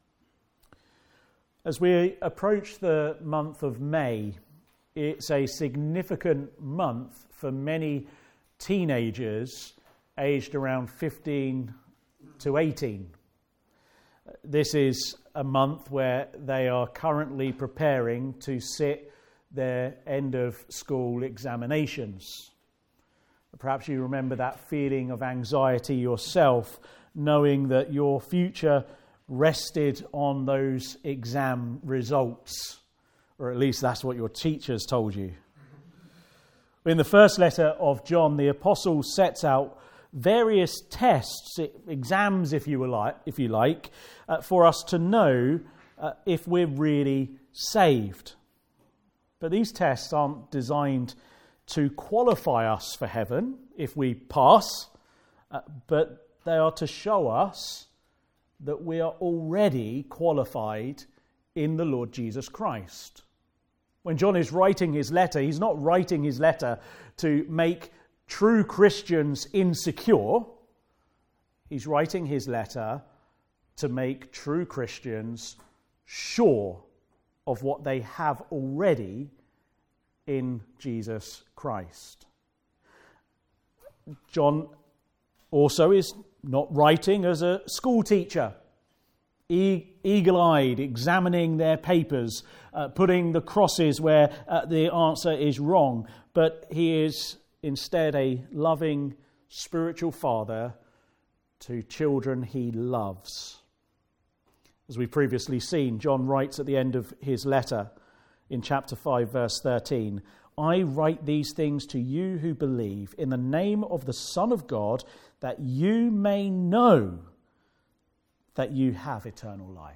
Service Type: Afternoon Service